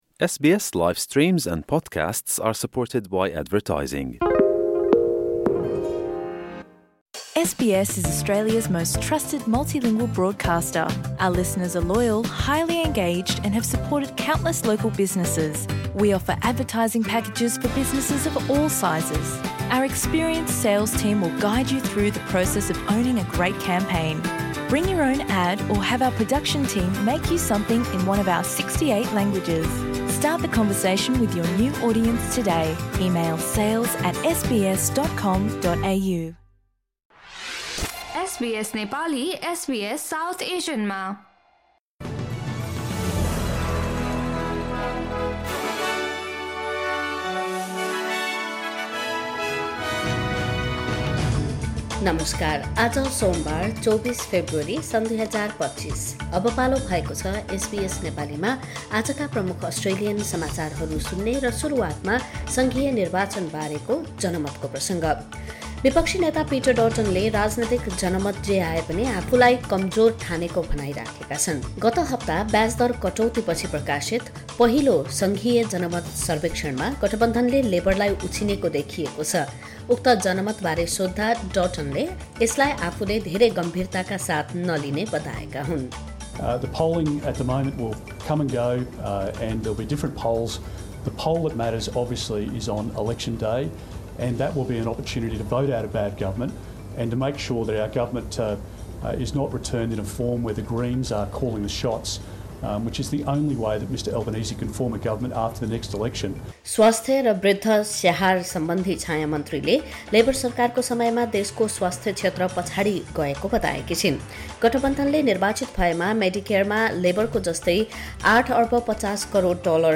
आजका प्रमुख अस्ट्रेलियन समाचार छोटकरीमा सुन्नुहोस्।